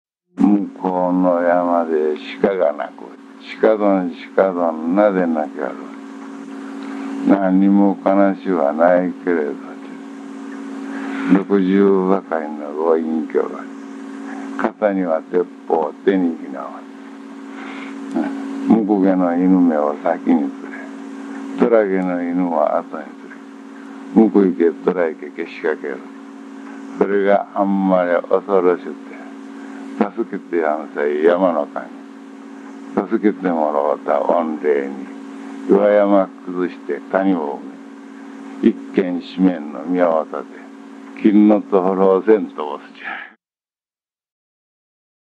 向こうの山で鹿が鳴く（子守歌・浜田市三隅町古市場）
歌い手　男性・明治25年（1892）生
この歌を教えていただいたのは昭和35年（1960）のこと。
ゆったりとした口調で伝承者の男性はうたってくださった。